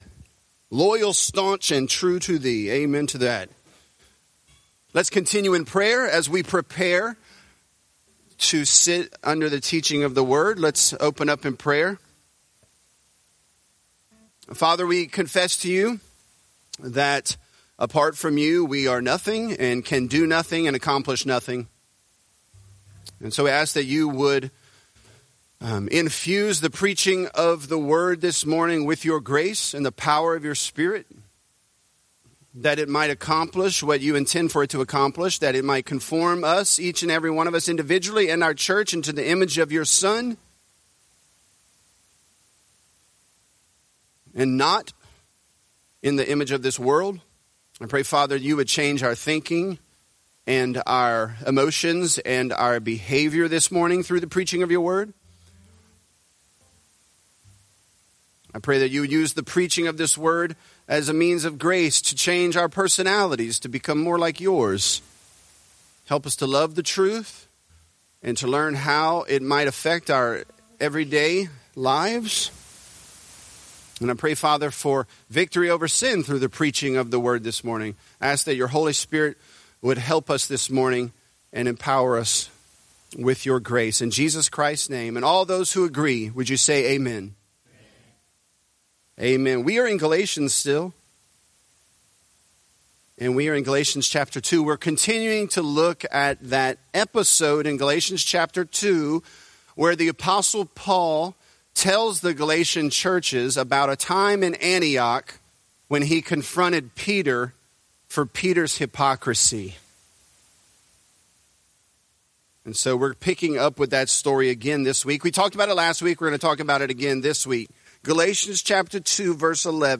Galatians: Biblical Confrontation | Lafayette - Sermon (Galatians 2)